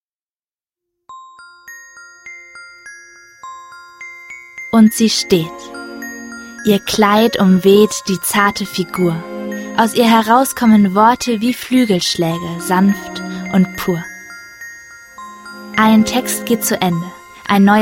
MP3-Hörbuch - Download